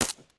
sounds / weapons / _bolt